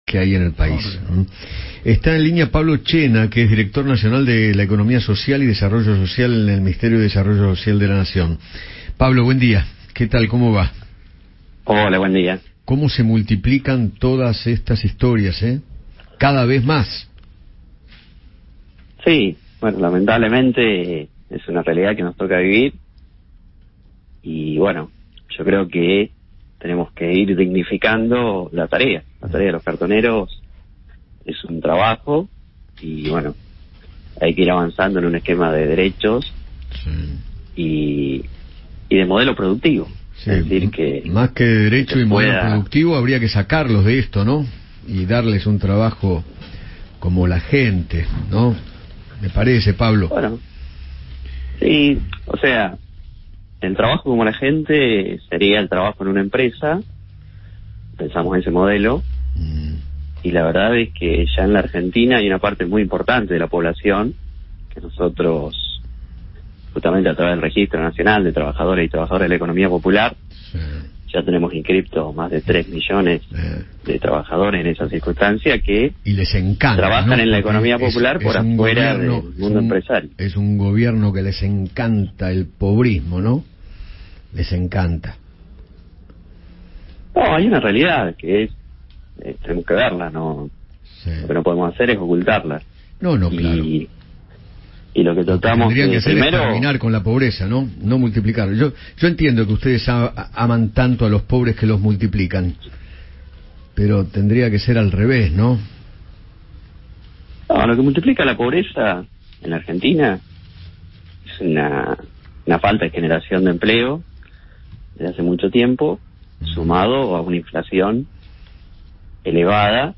Pablo Chena, director nacional de la Economía Social de Desarrollo Social, habló con Eduardo Feinmann sobre el aumento de la cantidad de personas que comenzaron a dedicarse a recolectar cartones y recalcó la necesidad de formalizar dicho trabajo.